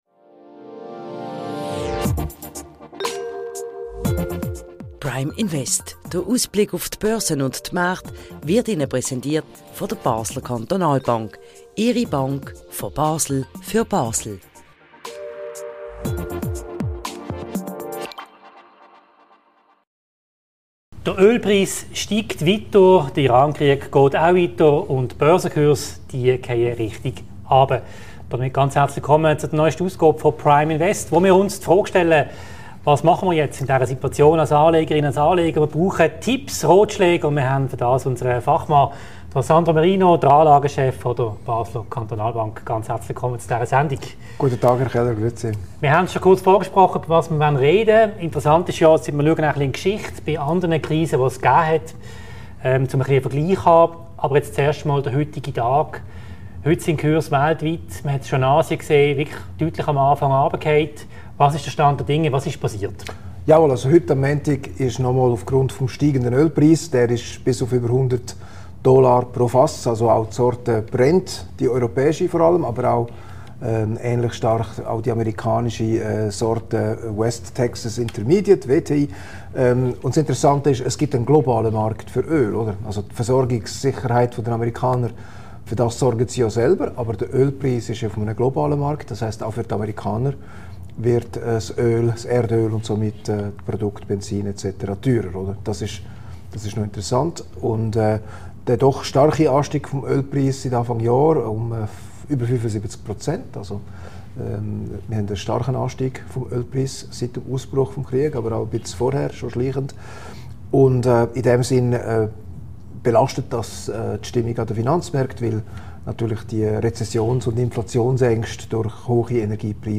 im Interview: Wie sollen die Anleger auf die Situation mit dem Krieg im Iran reagieren?